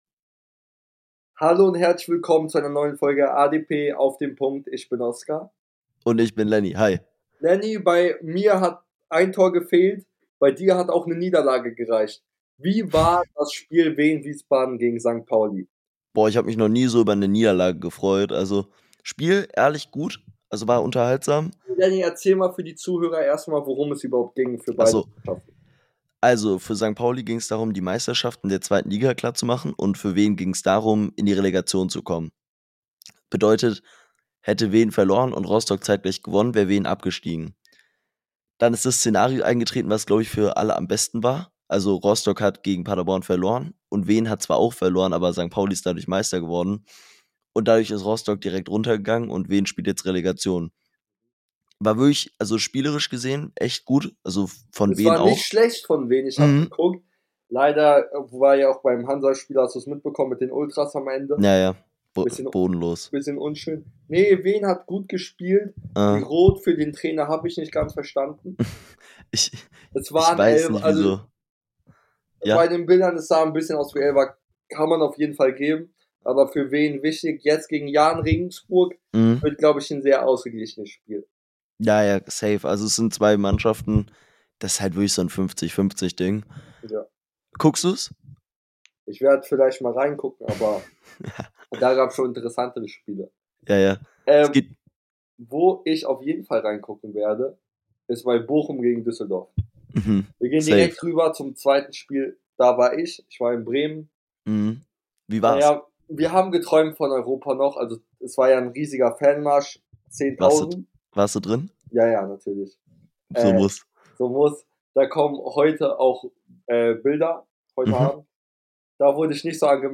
In der heutigen Folge reden die beiden Hosts über Kölns Abstiegs ,Unions Wahnsinn ,Hansas Abstieg und vieles mehr